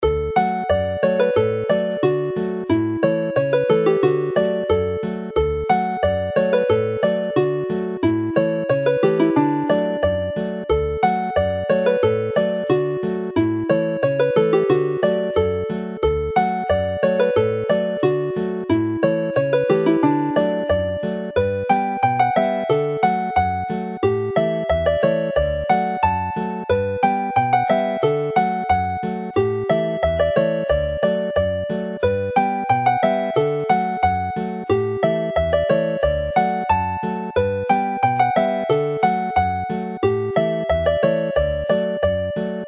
The version of Polca Cefn Coed included here is in D and gives a very cheerful conclusion to the set; it is closer to the original Llewelyn Alaw tune than the G version included in the set of polkas from April 2013.